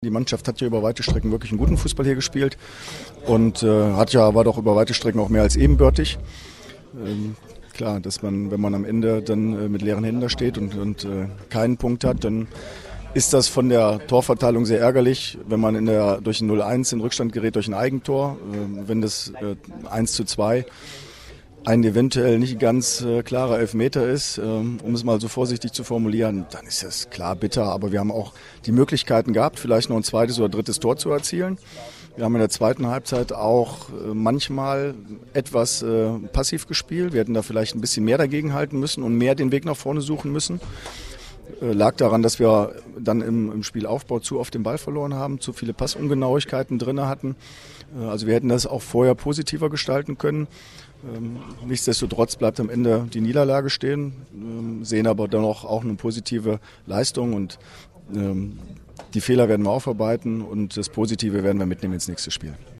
AUDIOKOMMENTAR
Chef-Trainer Andre Schubert zum Spiel